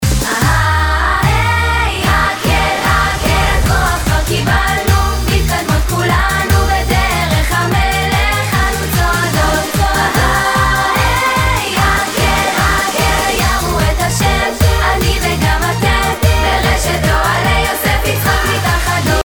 צלצול בנות גרסה 1
צלצול-בנות-1.mp3